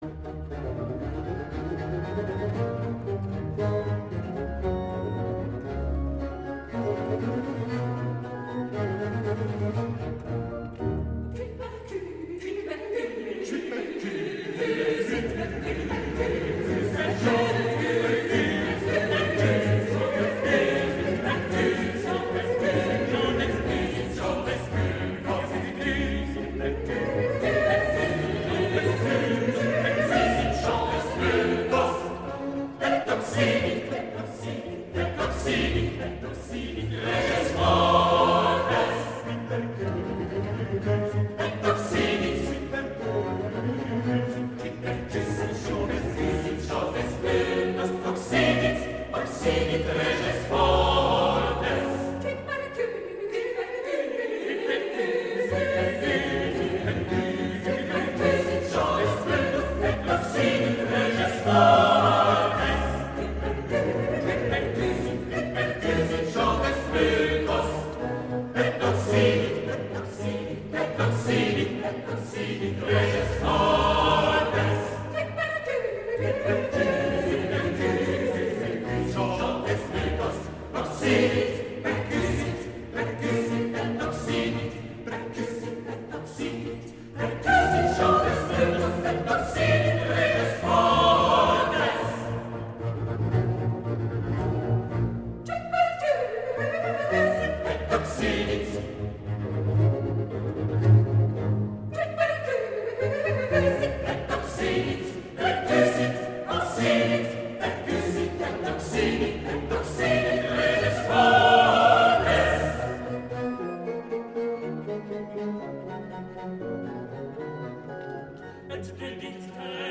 Grand motet
Prelude - Choeur, avec Trio